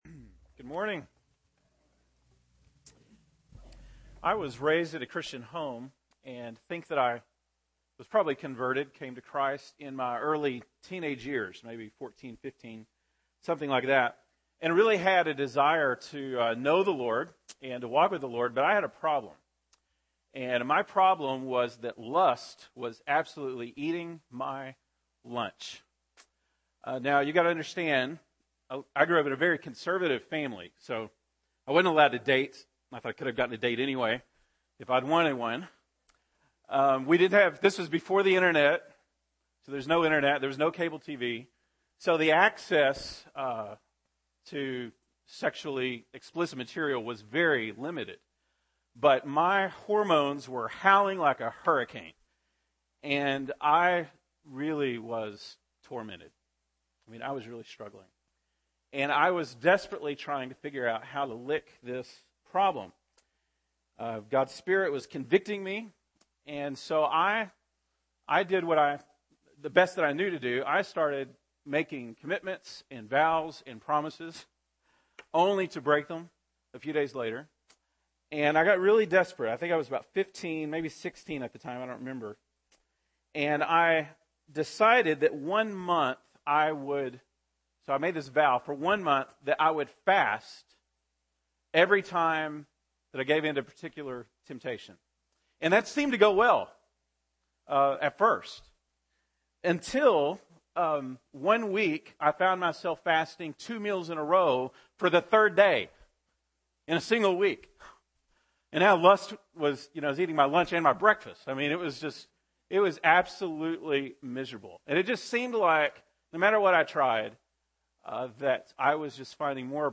February 23, 2013 (Sunday Morning)